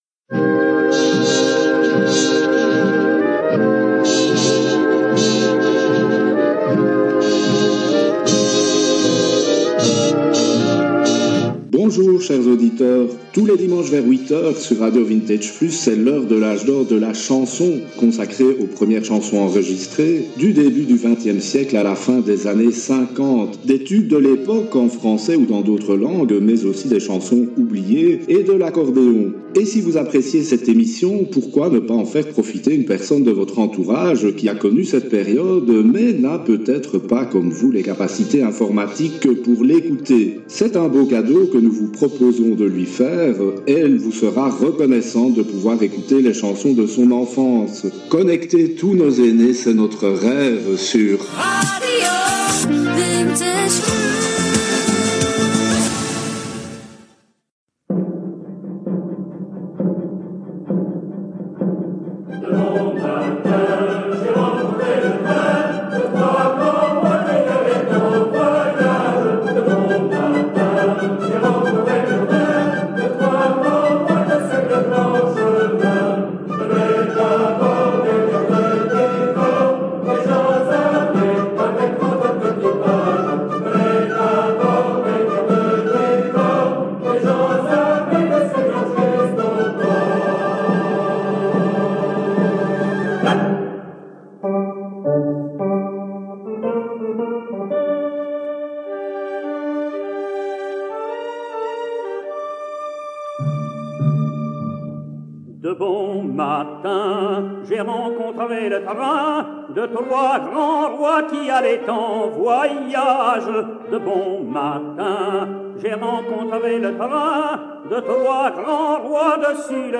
L’heure de l’âge d’or de la chanson est une émission hebdomadaire du dimanche matin à 8h sur RADIO VINTAGE PLUS, consacrée aux premières chansons enregistrées, du début du 20ème siècle aux années 50’s.